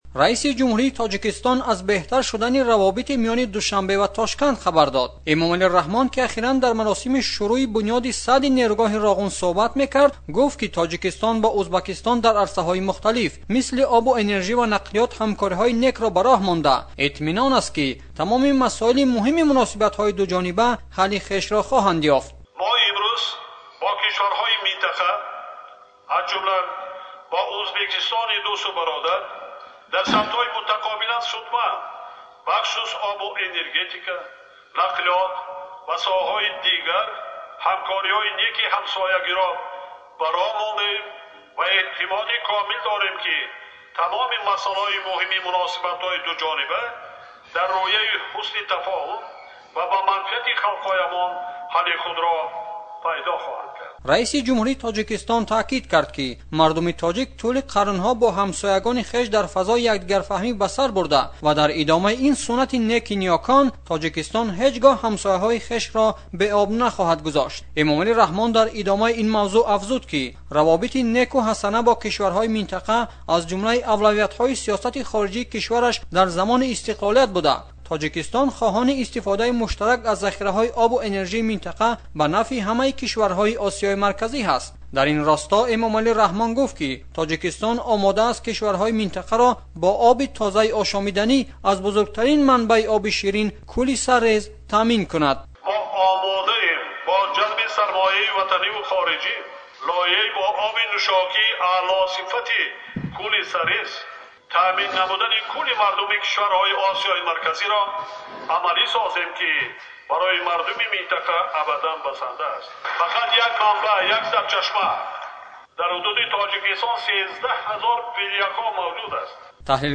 Эмомалӣ Раҳмон, раисиҷумҳури Тоҷикистон дар маросими оғози сохти нерӯгоҳи барқи обии Роғун, аз беҳбуди равобит миёни Тоҷикистон ва Узбакистон хабар дод.